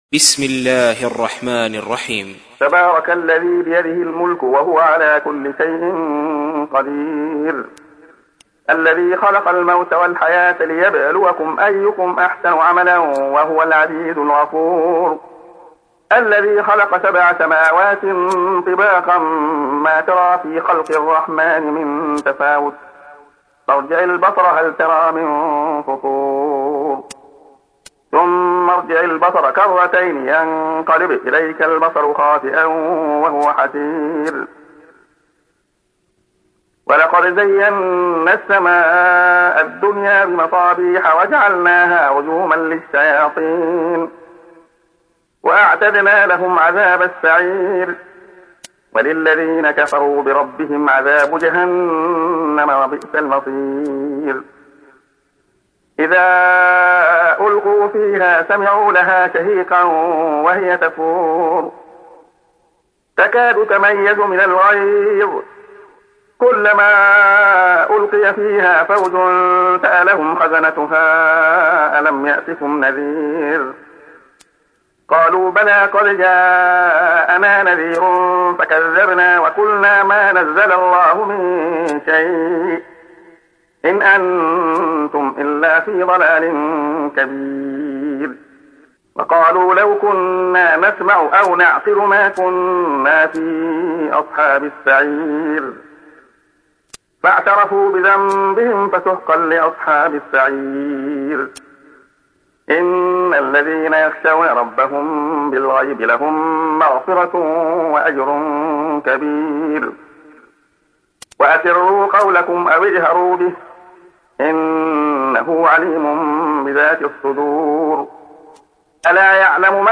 تحميل : 67. سورة الملك / القارئ عبد الله خياط / القرآن الكريم / موقع يا حسين